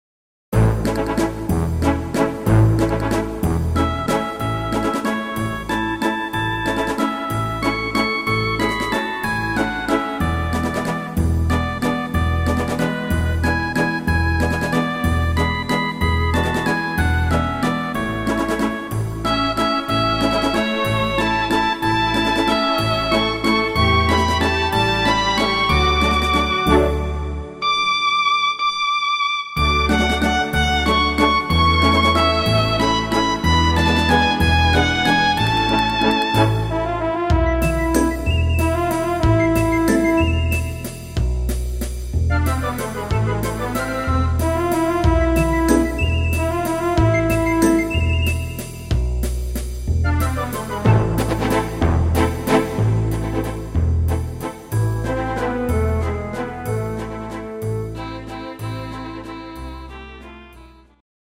instrumental Orchester